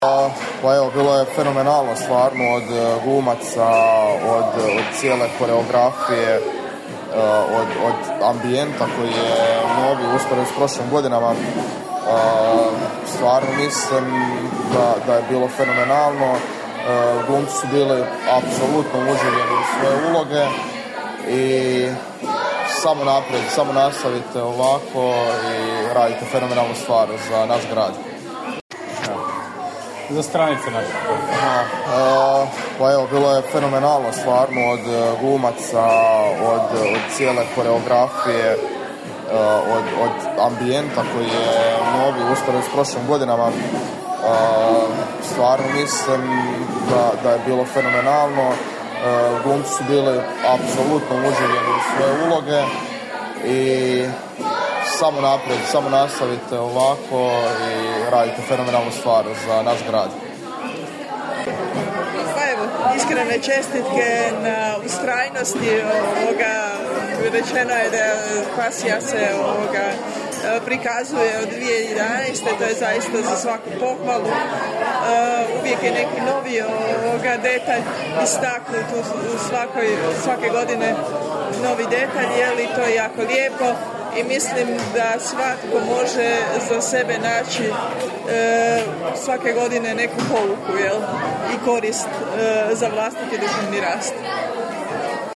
Nakon izvedbe smo razgovarali i s publikom.